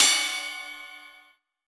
Ride (Tron Cat).wav